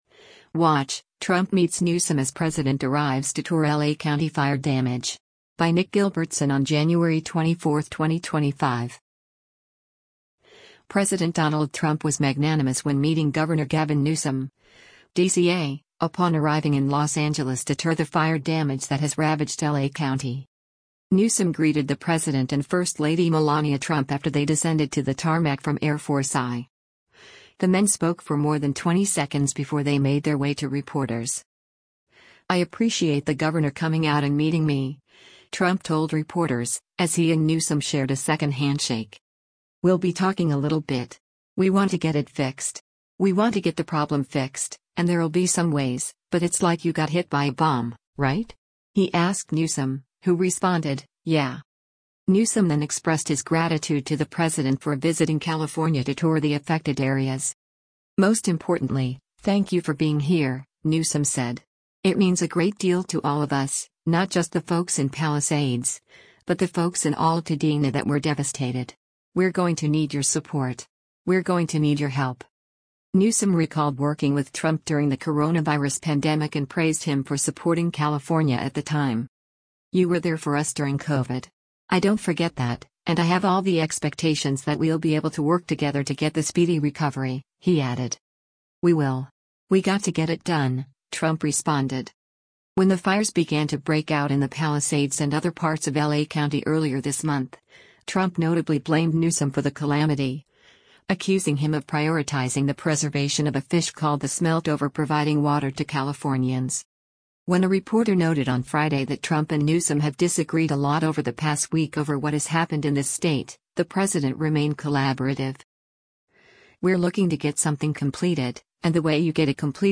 Newsom greeted the president and first lady Melania Trump after they descended to the tarmac from Air Force I. The men spoke for more than 20 seconds before they made their way to reporters.
“I appreciate the governor coming out and meeting me,” Trump told reporters, as he and Newsom shared a second handshake.